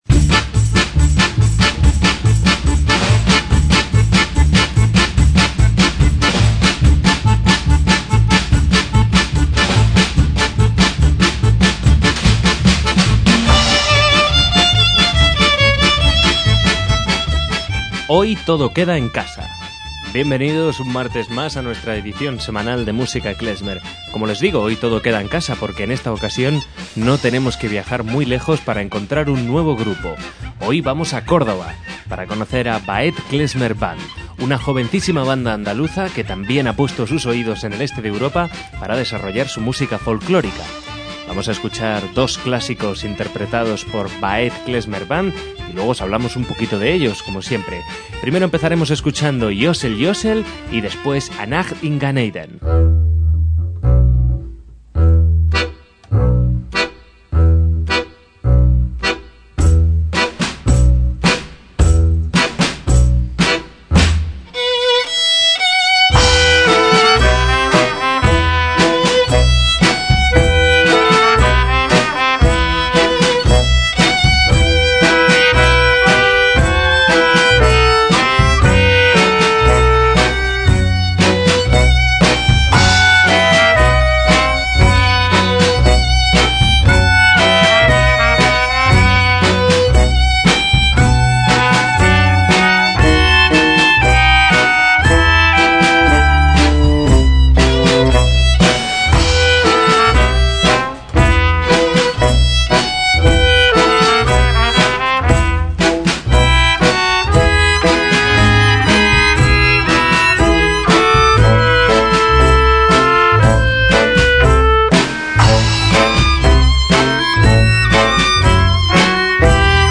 MÚSICA KLEZMER